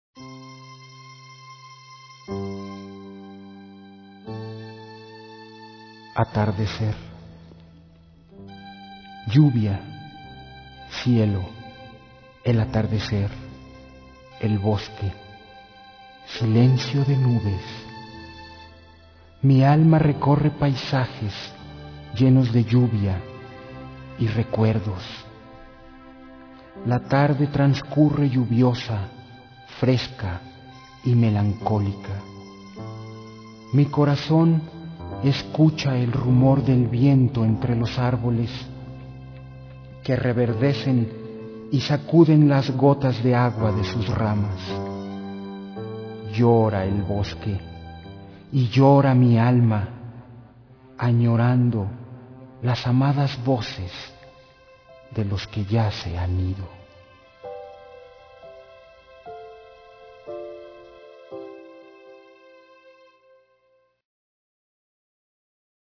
P O E M A S
C A L I D A D     M O N O